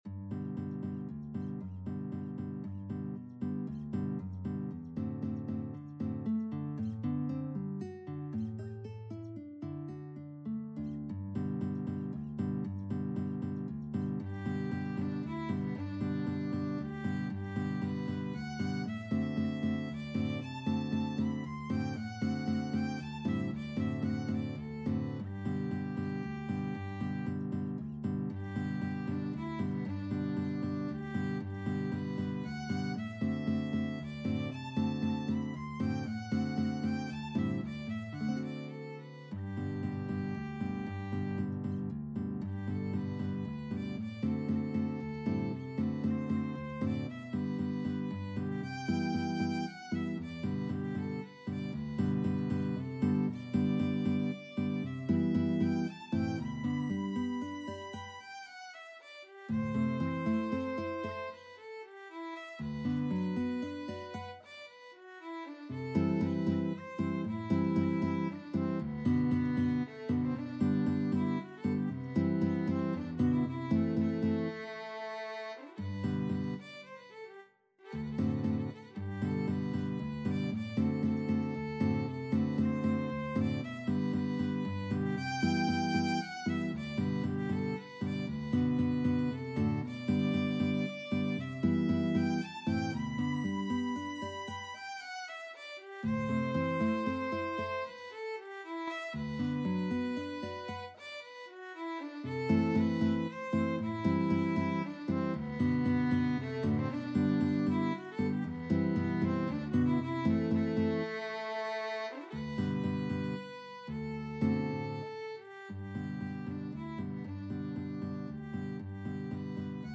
3/4 (View more 3/4 Music)
Allegretto =116 Allegretto =120
Classical (View more Classical Violin-Guitar Duet Music)